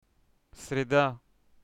רביעיSrida